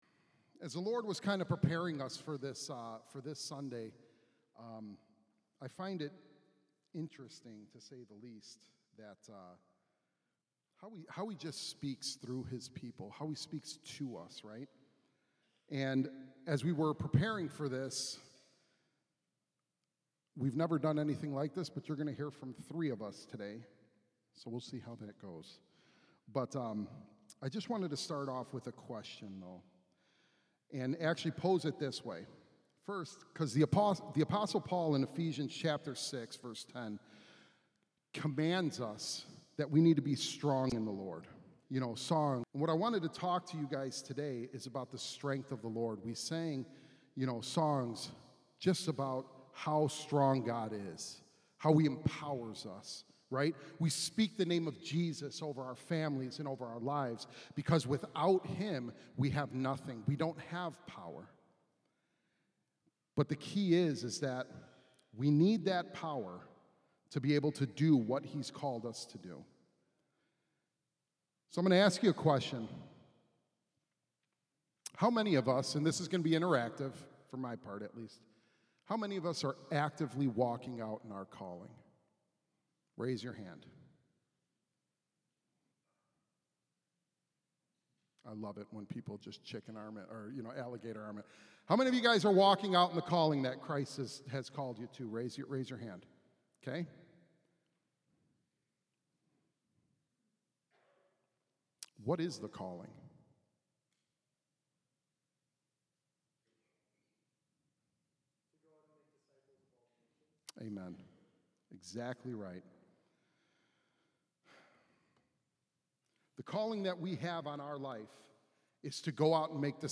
The Holy Spirit is given as a gift to all believers, but how fully do we open up and ask for His ownership and empowering of our lives? 3 of HRT’s elders teach how the Spirit fills us to carry out our calling, with streams of living water to refresh and strengthen us, and then to offer the hope, life and healing that only the Lord can give.